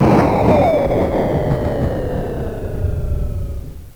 1 channel
explosion.mp3